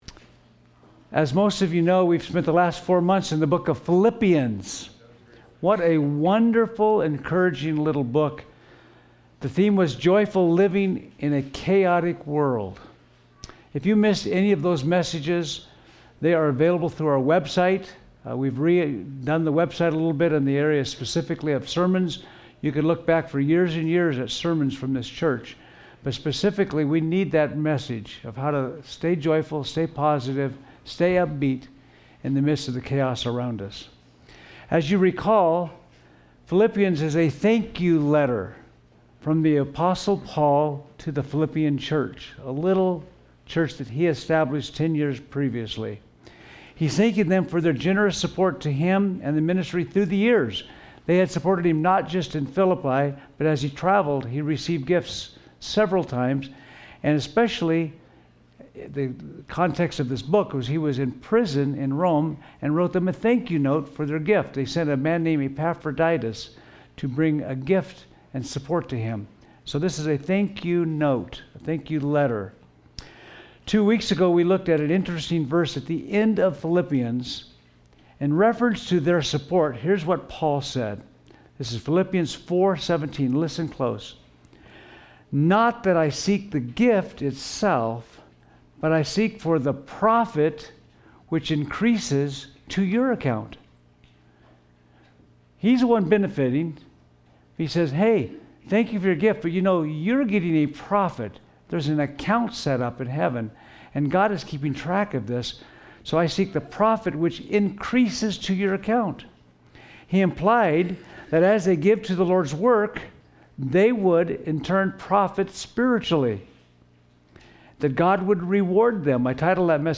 I apologize for the background buzzing at the beginning of the audio. Jump forward for about 18 minutes and it goes away.